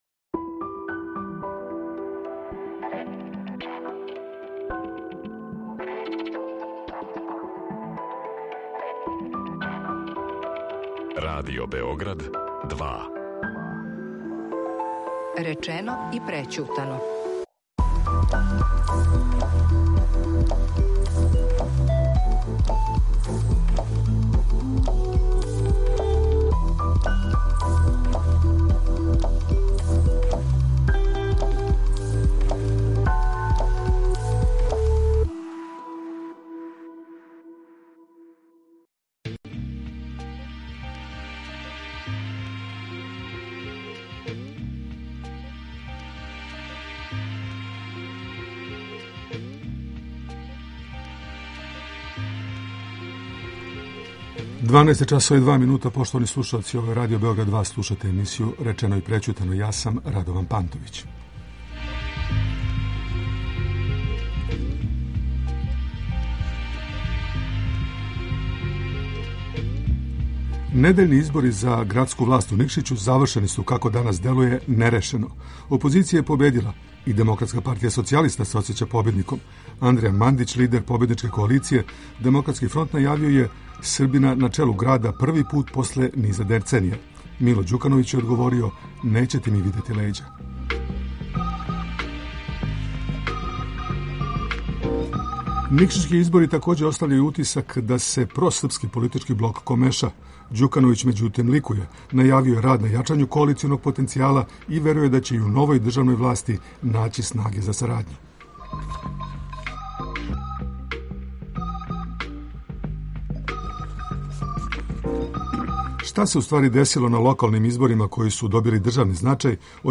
говоре Ђорђе Вукадиновић, политички аналитичар и доскорашњи народни посланик